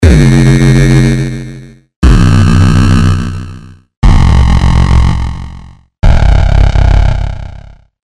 原始合成器产生的 " nameit3
描述：短暂的嗡嗡声
标签： 嗡嗡声 电子
声道立体声